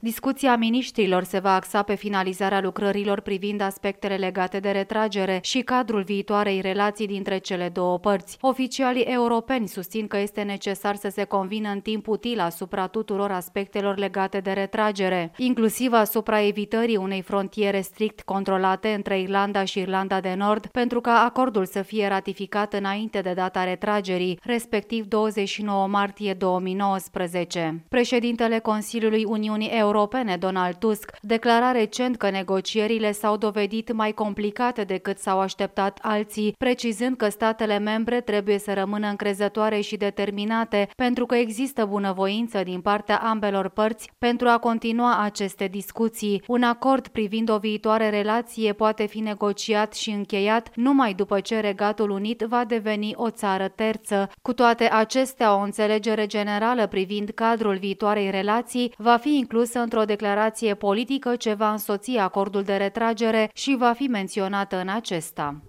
Astăzi, la Bruxelles